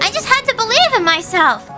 Worms speechbanks
Excellent.wav